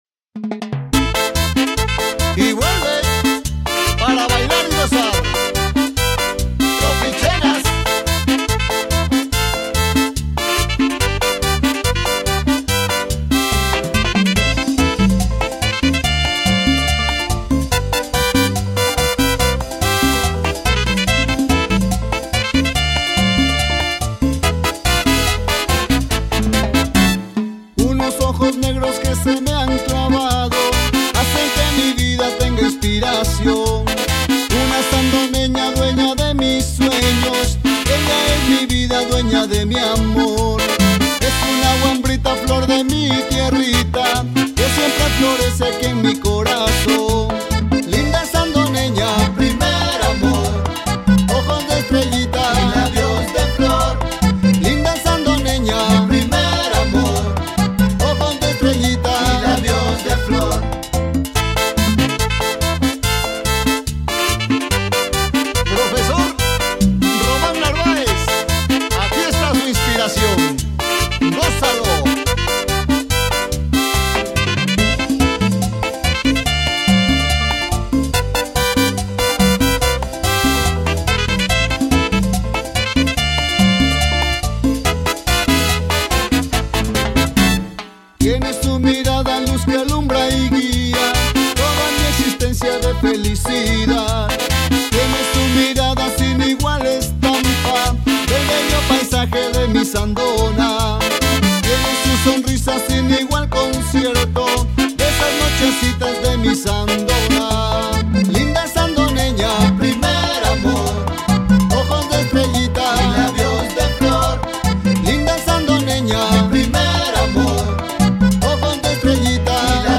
en ritmo de paseaito
piano
saxofón alto
saxofón tenor
trompetas
congas
güiro y la tambora
timbales
voces